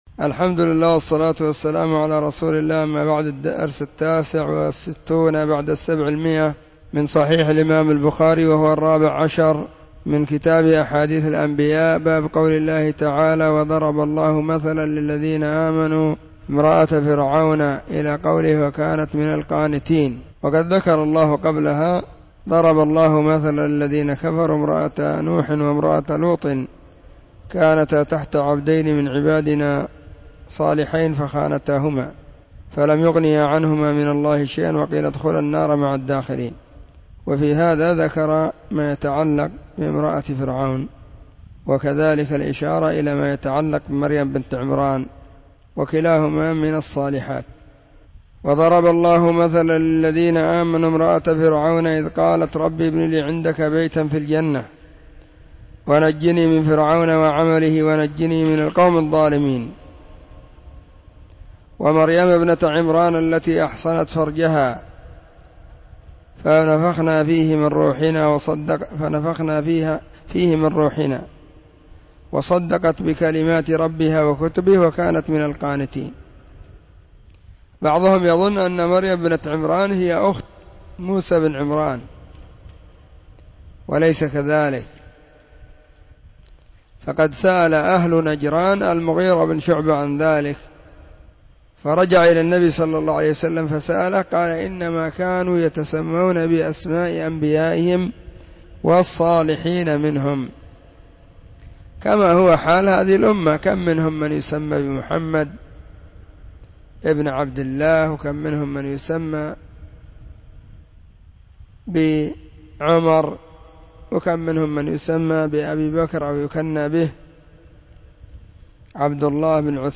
🕐 [بين مغرب وعشاء – الدرس الثاني]
🕐 [بين مغرب وعشاء – الدرس الثاني] 📢 مسجد الصحابة – بالغيضة – المهرة، اليمن حرسها الله.
كتاب-أحاديث-الأنبياء-الدرس-14.mp3